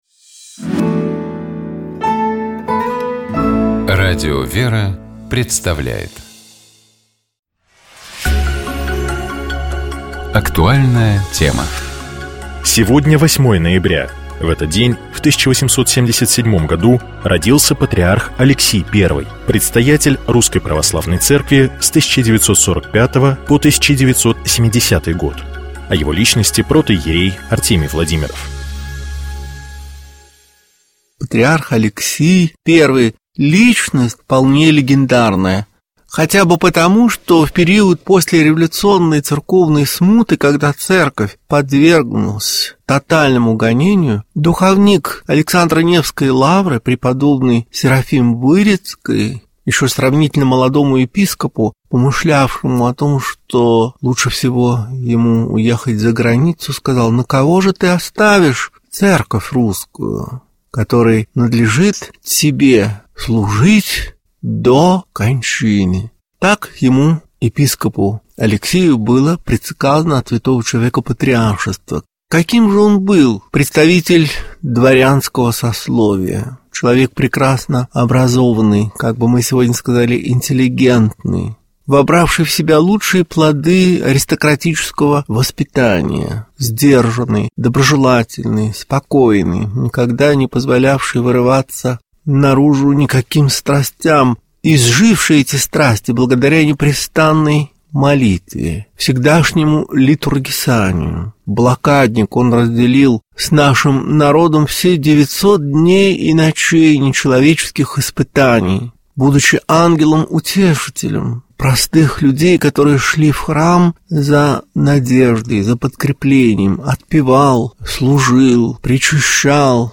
Известные актёры, режиссёры, спортсмены, писатели читают литературные миниатюры из прозы классиков и современников. Звучат произведения, связанные с утренней жизнью человека.